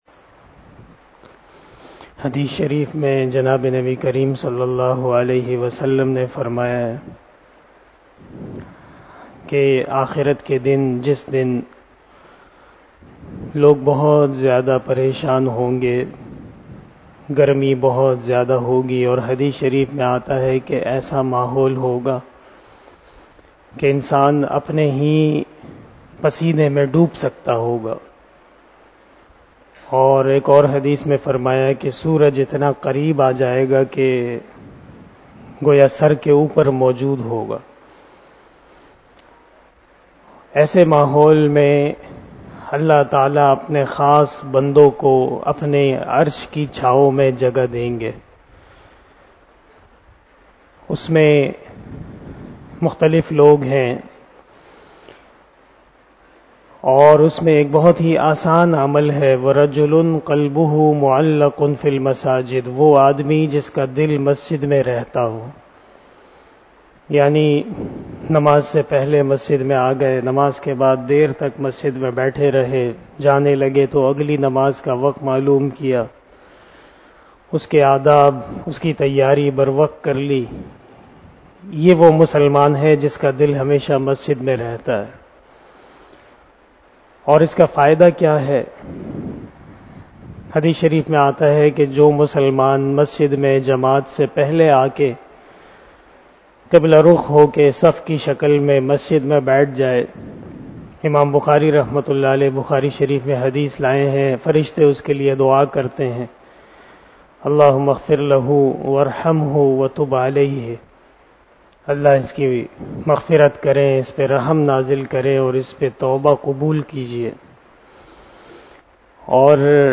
026 After Asar Namaz Bayan 04 April 2022 ( 02 Ramadan 1443HJ) Monday